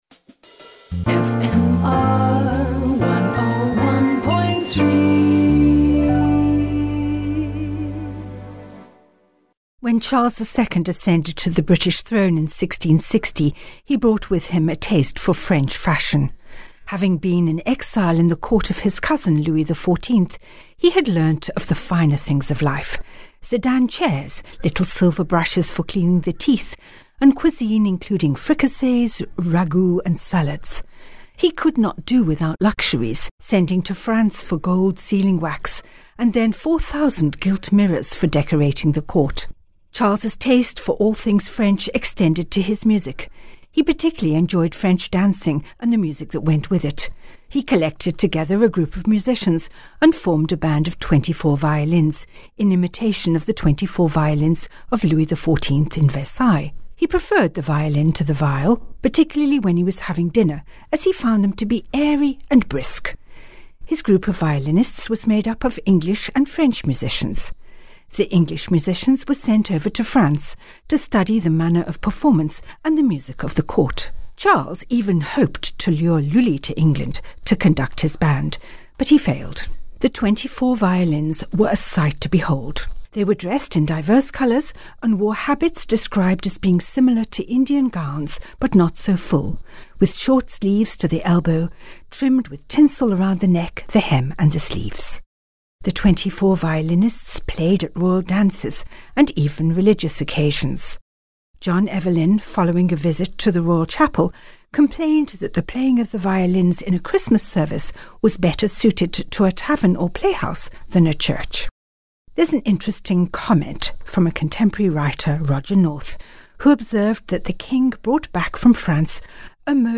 Title: The Kirkman Dynasty Tracks: J.C. Bach’s Sonata for harpsichord in G major, Op. 5 No. 3: II. Allegretto Artist